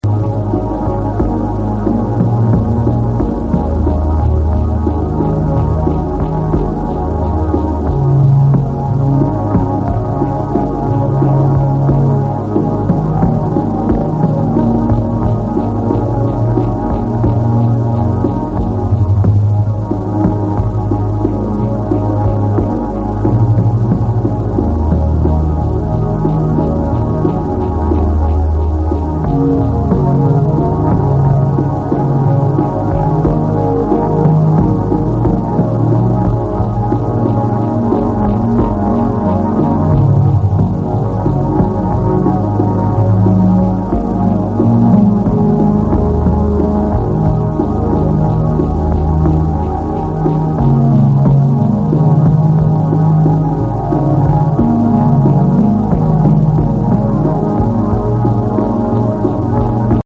suspenseful and gloomy